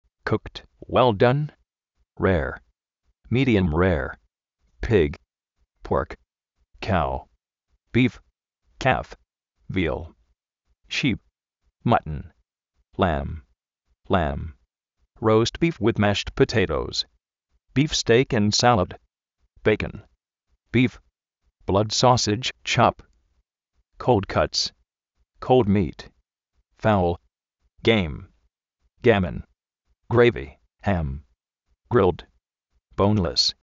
kúkt (uél dán),
róust bíf